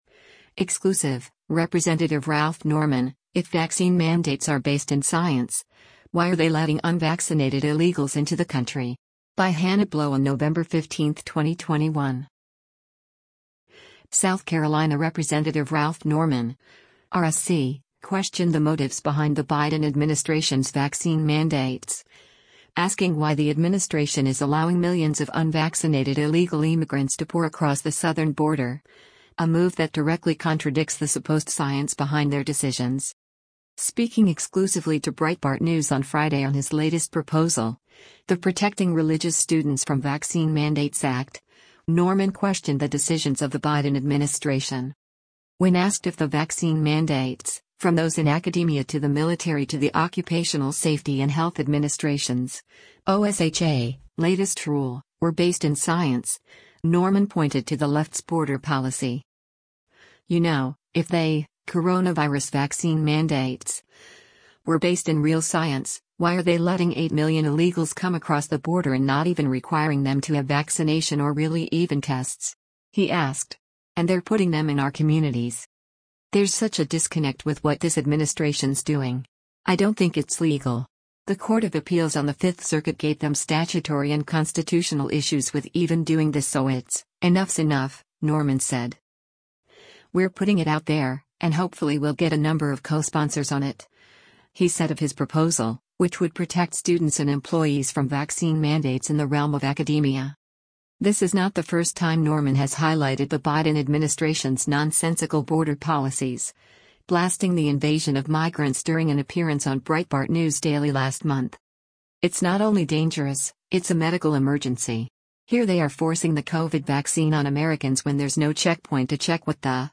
Speaking exclusively to Breitbart News on Friday on his latest proposal, the Protecting Religious Students from Vaccine Mandates Act, Norman questioned the decisions of the Biden administration.